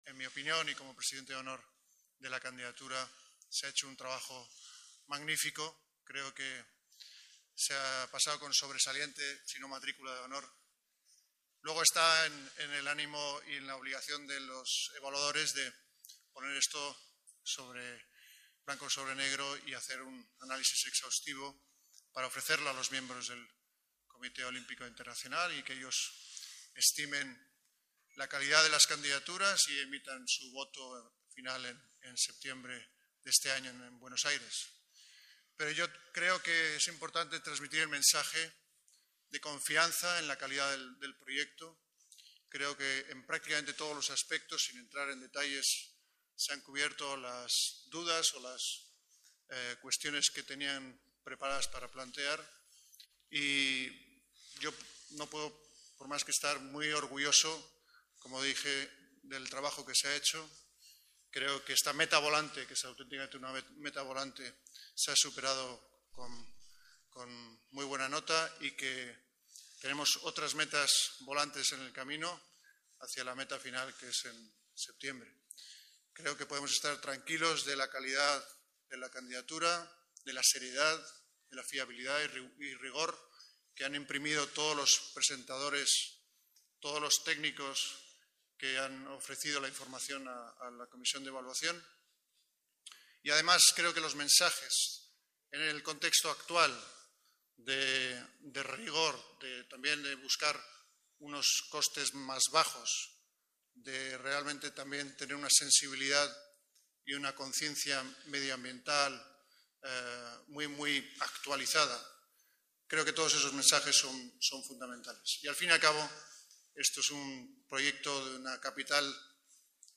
Nueva ventana:Declaraciones del Príncipe de Asturias: Candidatura de sobresaliente